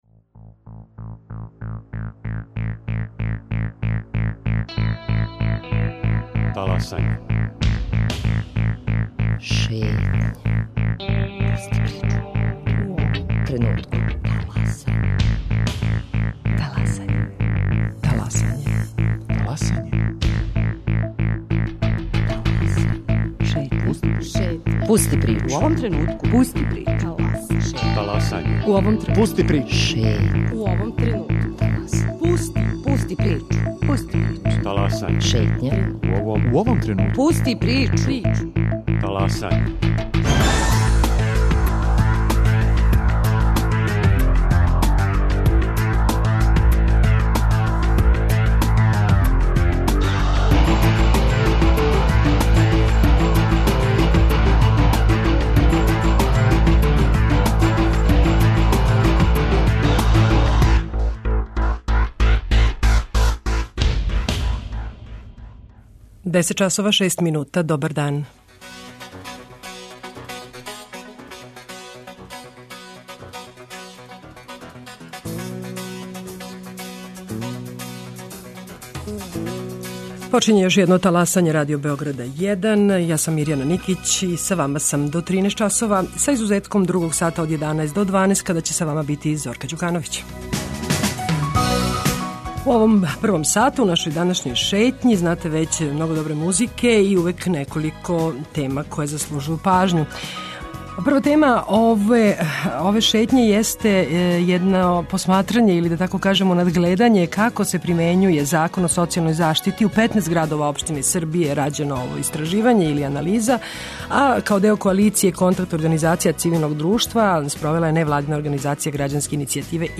Прошетаћемо Србијом, и у разговору са новинарима градских радио станица сазнати шта се догађа ових дана у Врању, Зајечару, Кикинди....